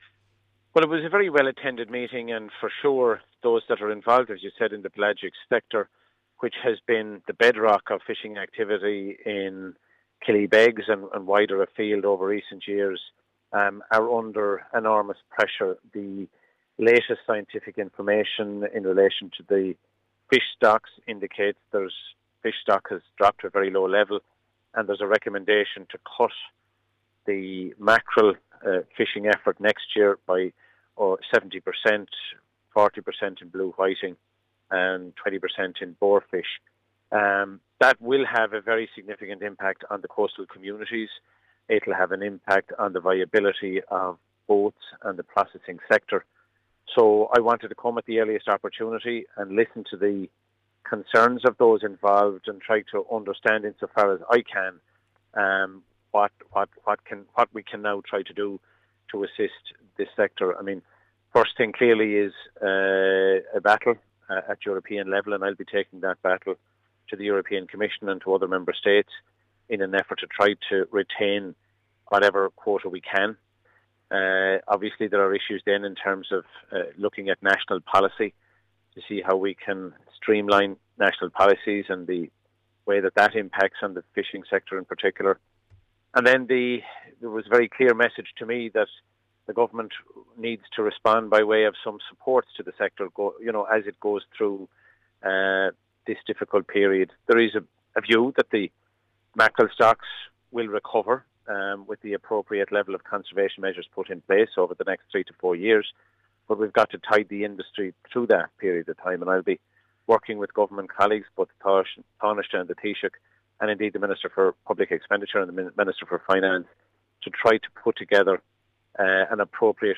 Fish quota reductions “deeply disturbing” – Minister Dooley during visit to Killybegs
Minister Dooley has outlined what needs to be done to support the industry: